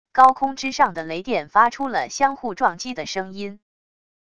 高空之上的雷电发出了相互撞击的声音wav音频